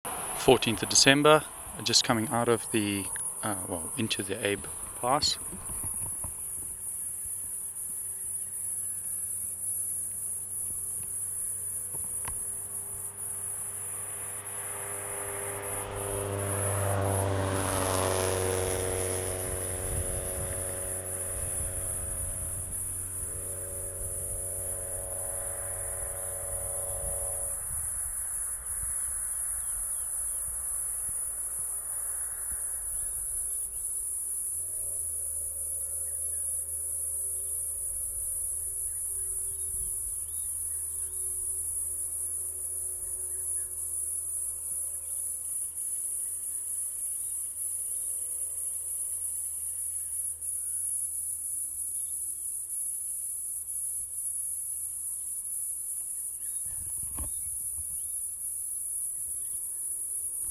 963: Platypleura mijburghi
Africa: South Africa: Limpopo: R36 Abel Erasmus Pass
MHV 963 P.mijburghi Abel Erasmus Pass.wav